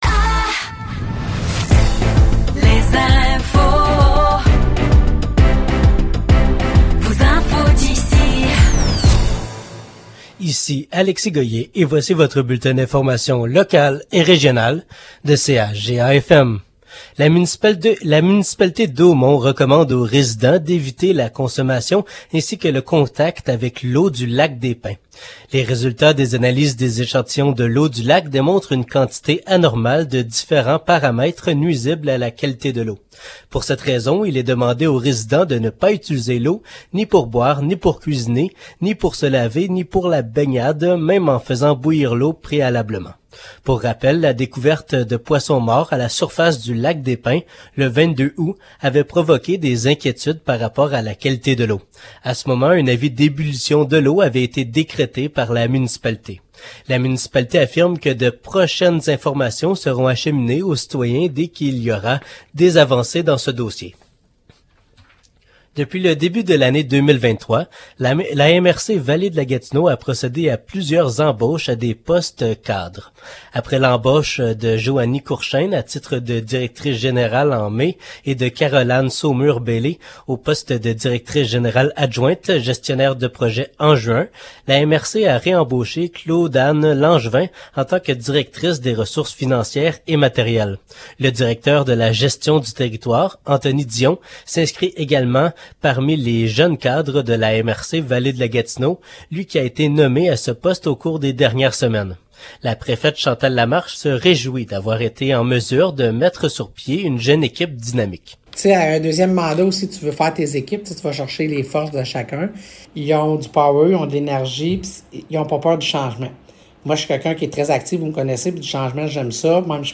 Nouvelles locales - 11 septembre 2023 - 15 h